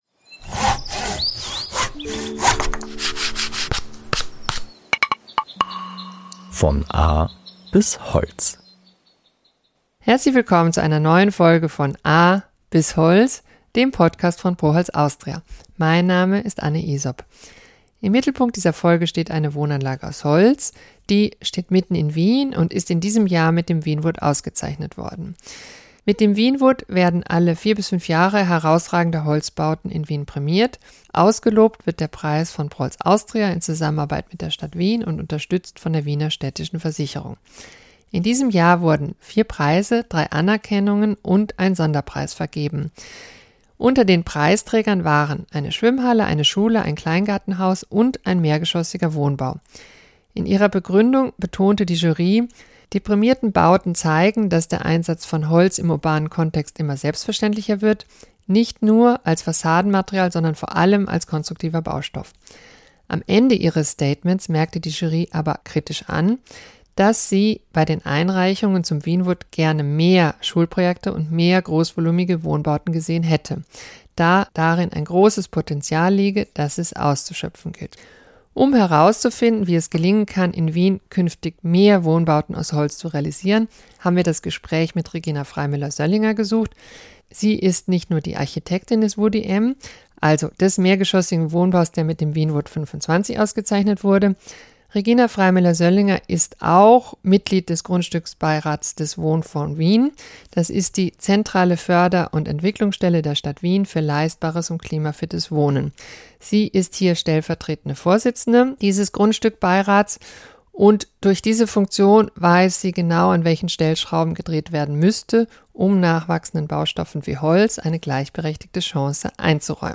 Der mehrgeschossige Wohnbau Woody M zählt zu den Preisträgern des wienwood 2025. Wie können in Wien mehr große Wohnbauten aus Holz entstehen? Ein Gespräch